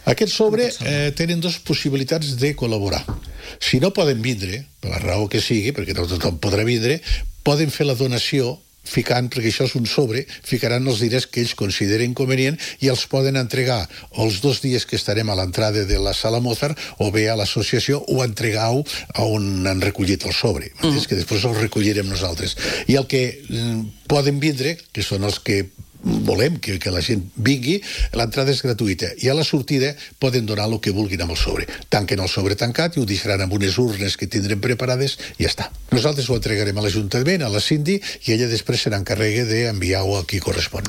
ha explicat en una entrevista a RCT que l’objectiu és omplir la sala i recaptar el màxim de fons possibles.